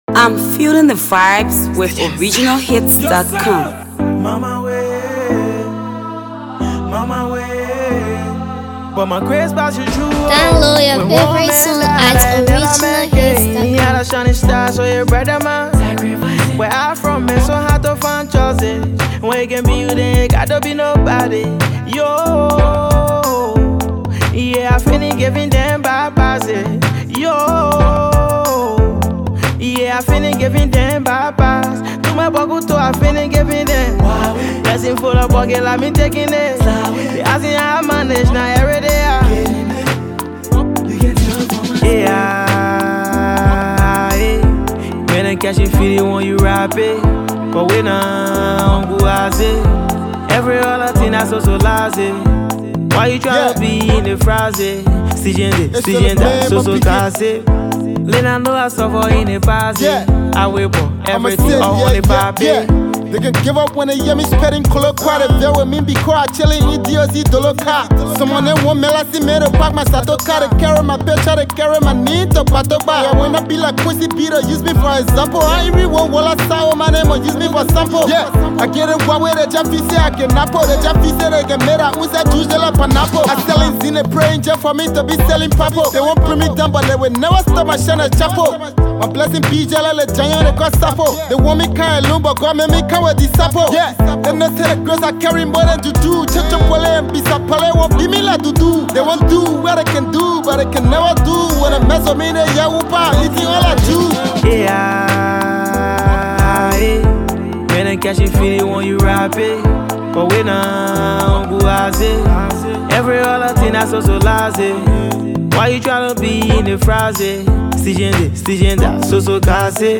studio effort